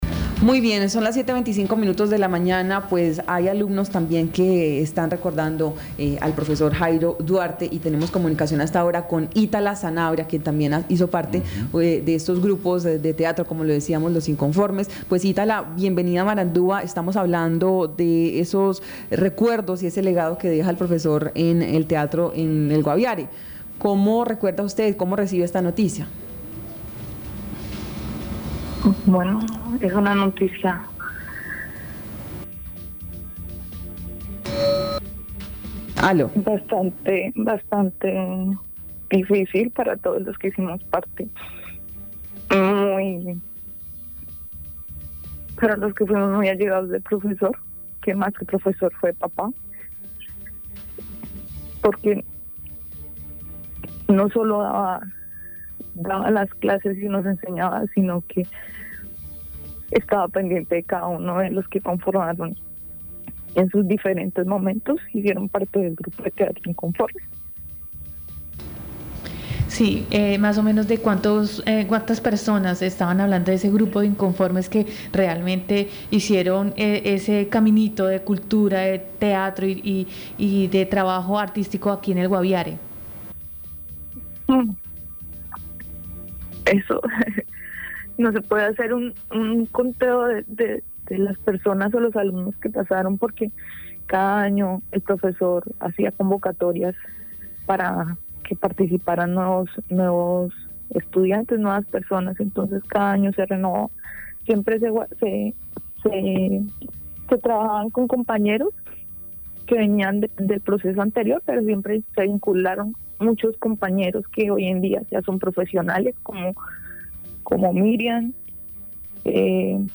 Escuche a Alexander Quevedo, secretario de Educación del Guaviare.